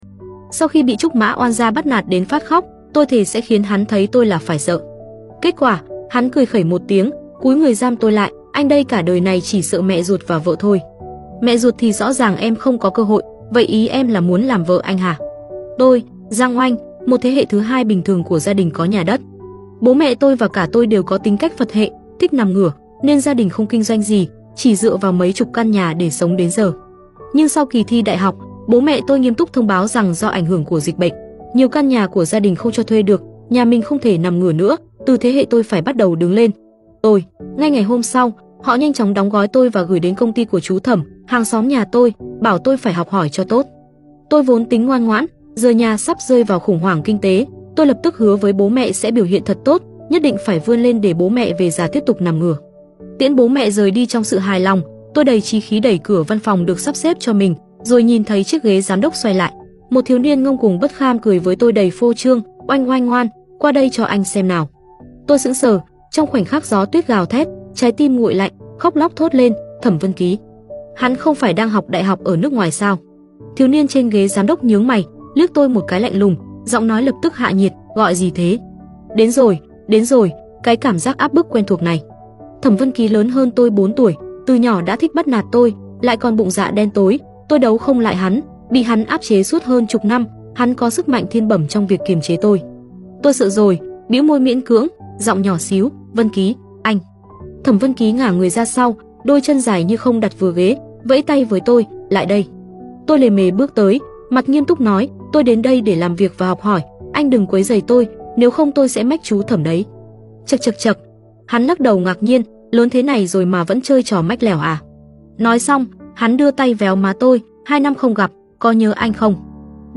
[Truyện Audio] Full:Mối tình thơ sound effects free download